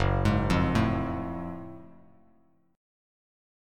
F#mM9 chord